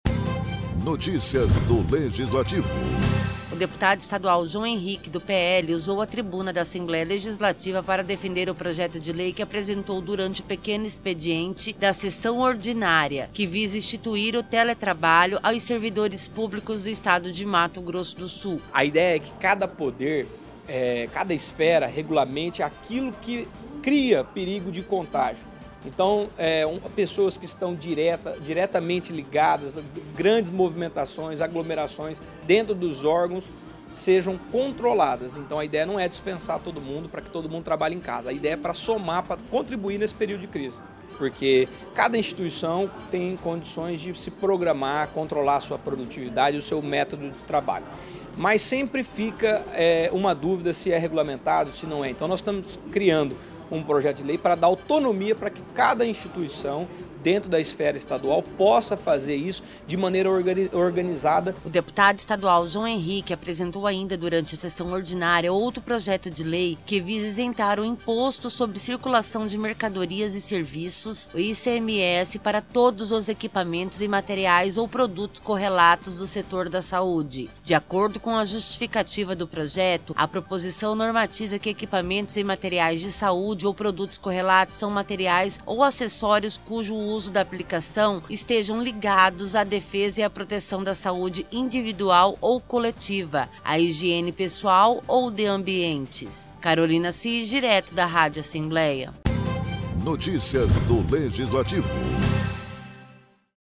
O deputado estadual João Henrique, do PL, usou a tribuna da Assembleia Legislativa para defender o projeto de lei que visa instituir o teletrabalho aos servidores públicos do Estado de Mato Grosso do Sul, e também outro projeto de lei de sua autoria, que visa isentar o Imposto sobre Circulação de Mercadorias e Serviços (ICMS) para todos os equipamentos e materiais ou produtos correlatos do setor da Saúde.